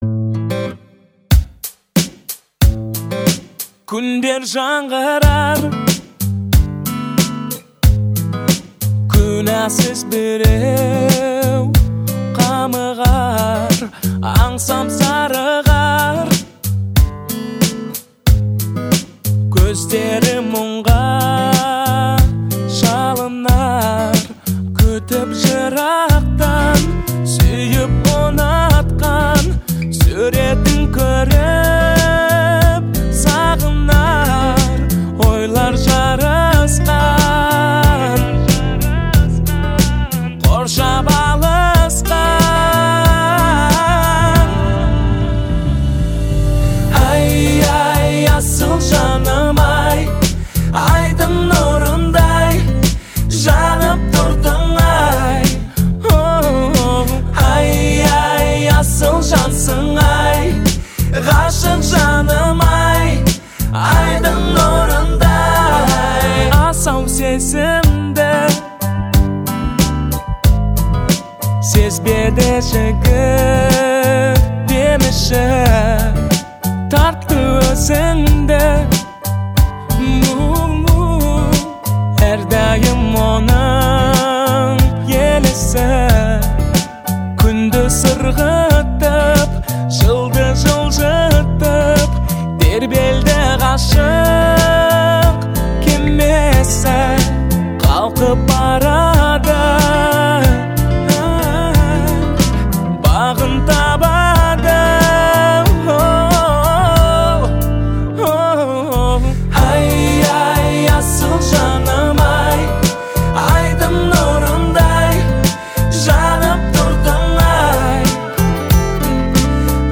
это трогательный казахский романтический трек